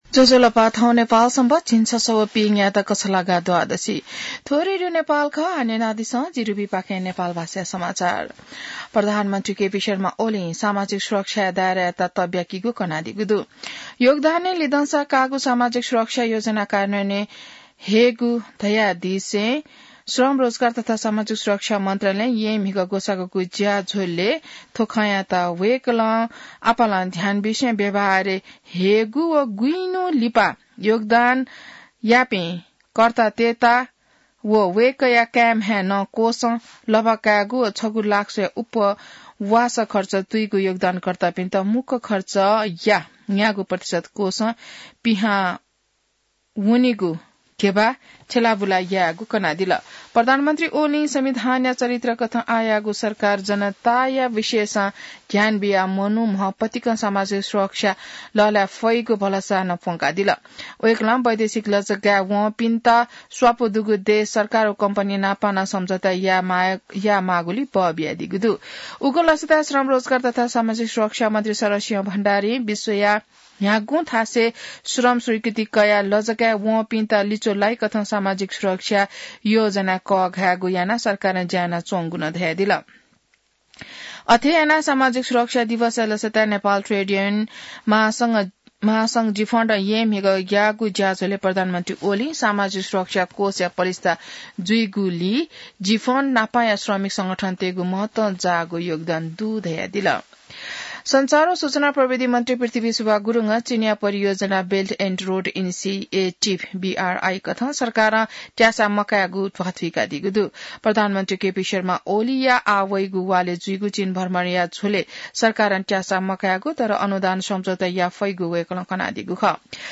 नेपाल भाषामा समाचार : १३ मंसिर , २०८१